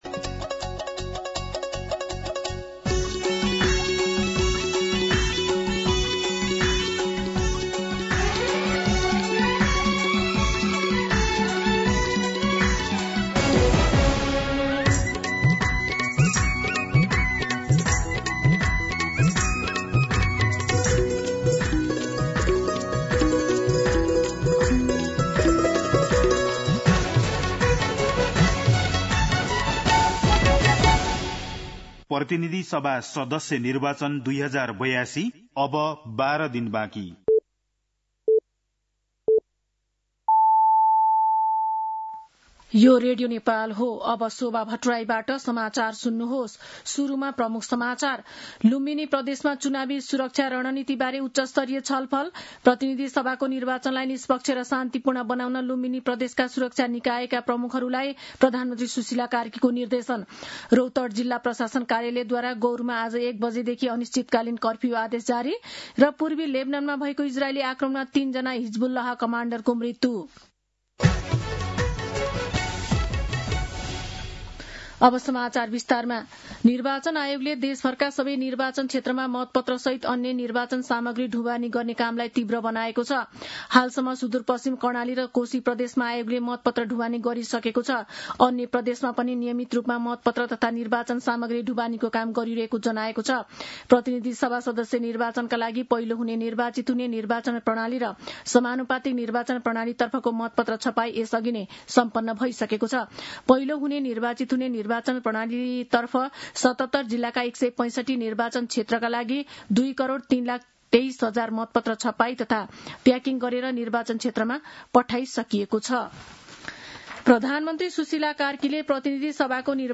दिउँसो ३ बजेको नेपाली समाचार : ९ फागुन , २०८२
3pm-Nepali-News-1.mp3